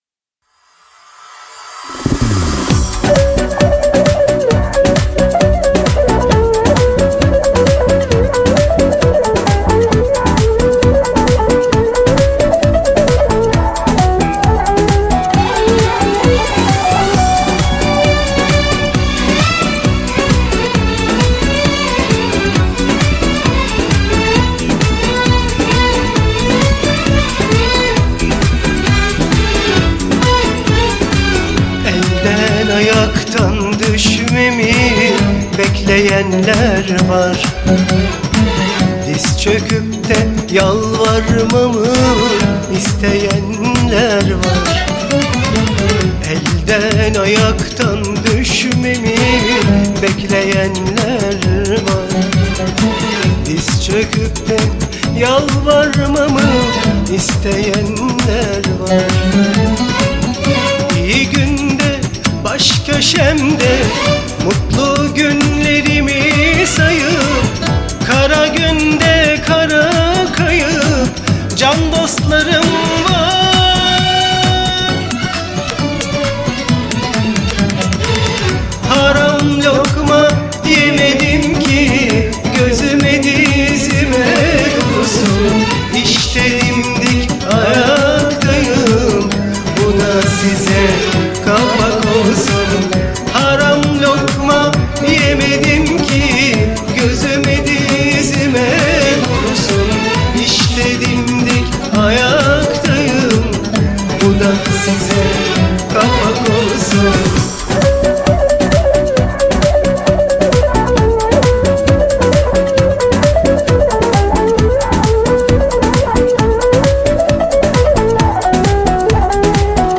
Pop Fantazi